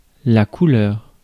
Ääntäminen
France (Paris): IPA: [la ku.lœʁ]